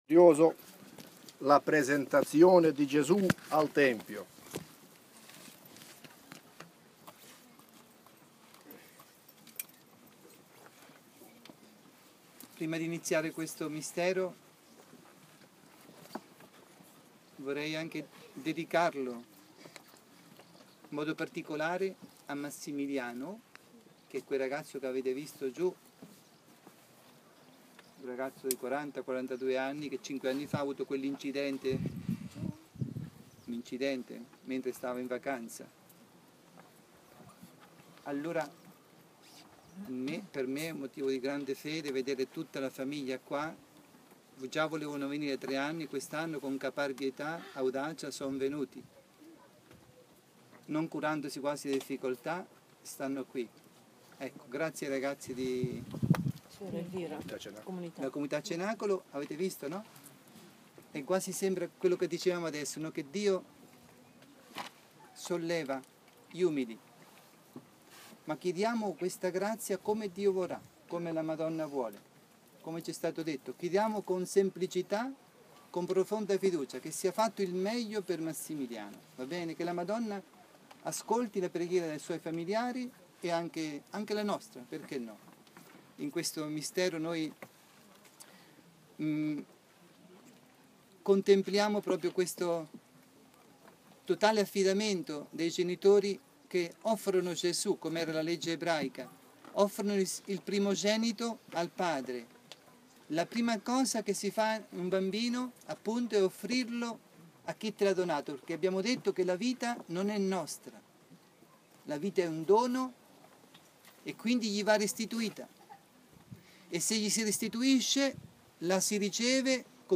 Pellegrinaggio a Medugorje del 15-20 maggio 2015
Sabato 16 maggio, ROSARIO  sulla collina delle apparizioni (Podbrdo)